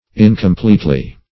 Incompletely \In`com*plete"ly\, adv.